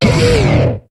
Cri de Hariyama dans Pokémon HOME.